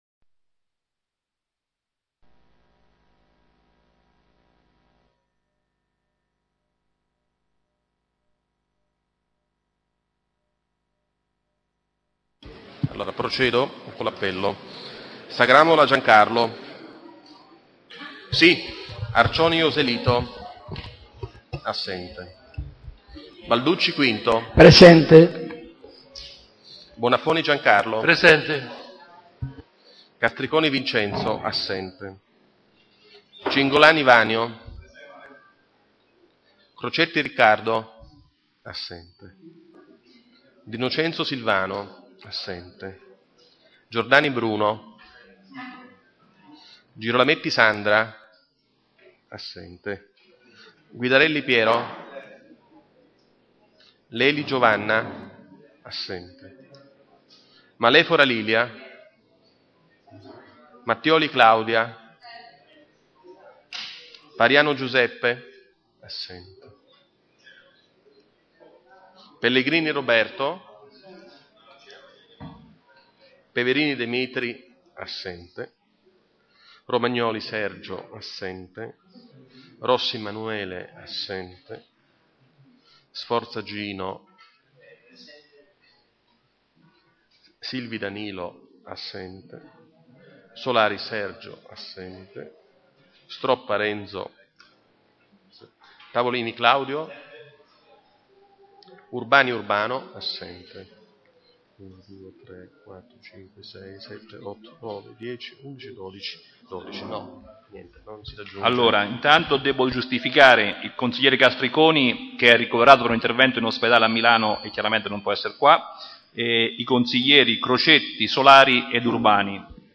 FILE AUDIO DELLA SEDUTA